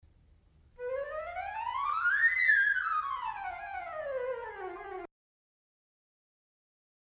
Il flauto possiede un suono leggero e rotondo ma corposo e robusto nel registro grave e non eccessivamente penetrante.
suono del flauto
flauto_suono.mp3